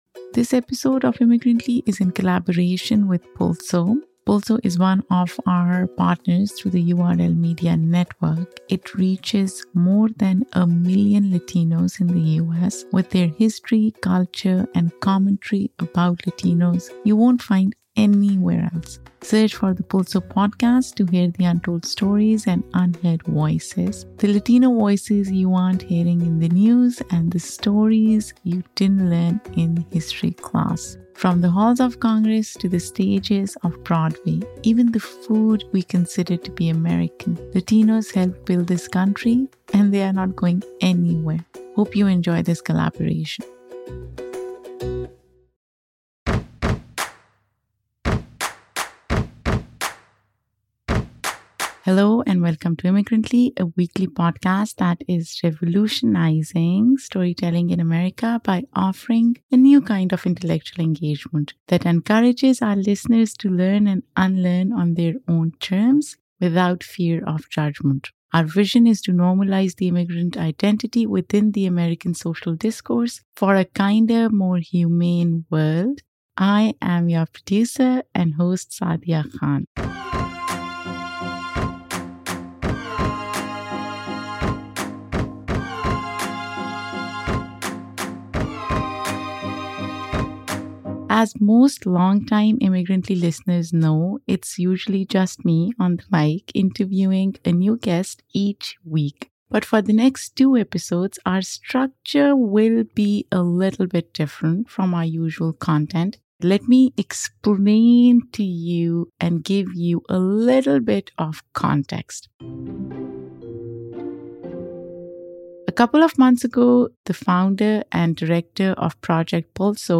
This narrative-style story will slightly differ from Immigrantly’s usual content, but it’s for a good reason.